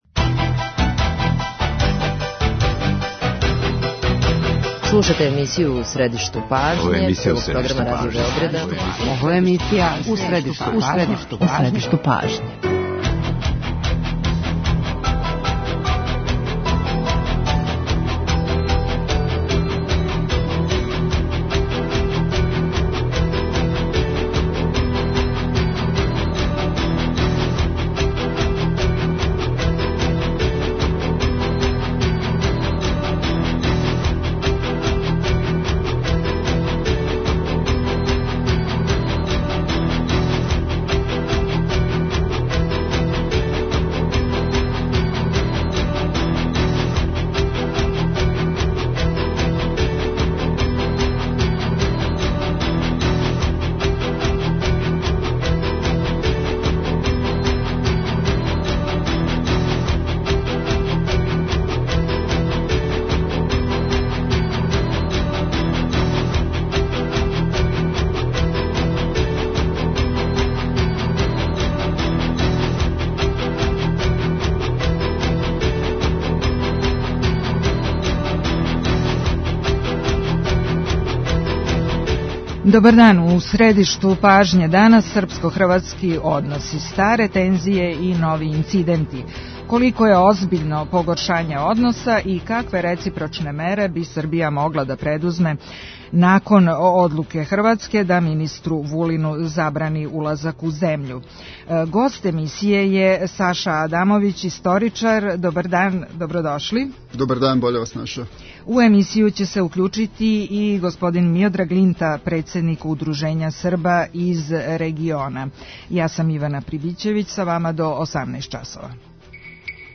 Гости емисије су Миодраг Линта, председник Савеза Срба из региона